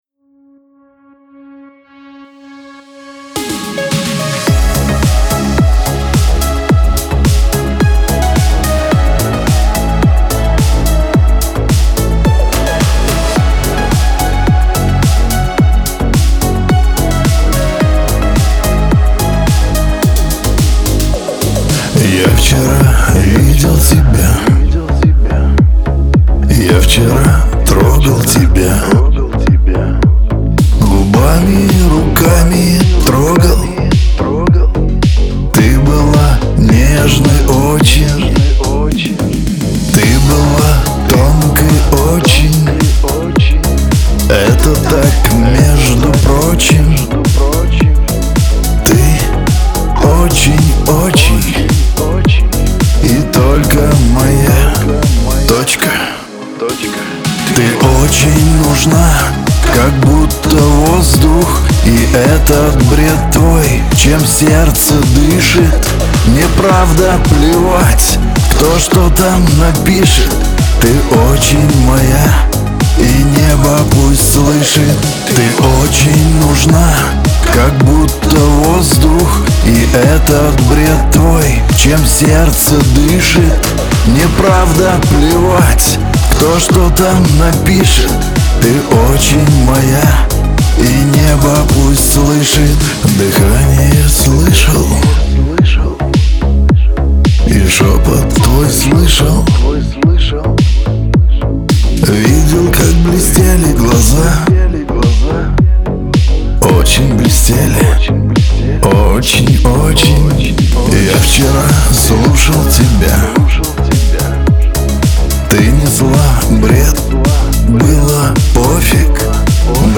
Лирика , Шансон